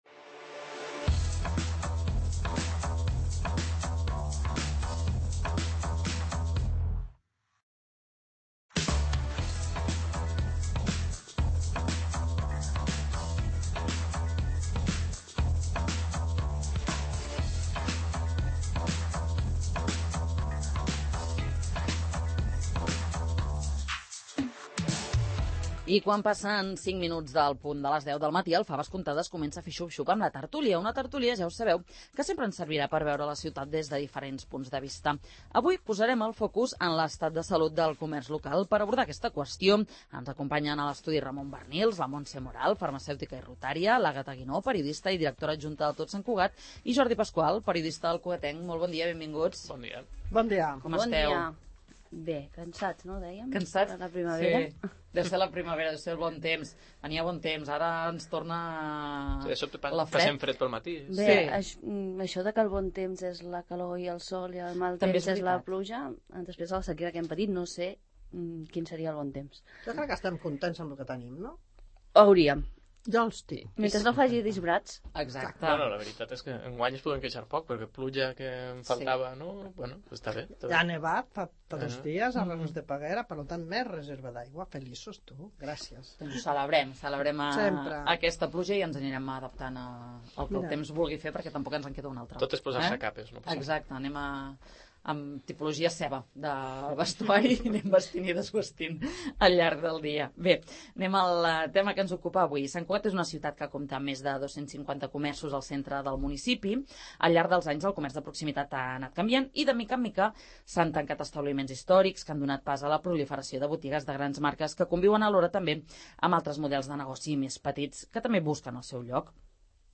Quin seria el millor model per al comer� de Sant Cugat? En parlem a la tert�lia del 'Faves comptades'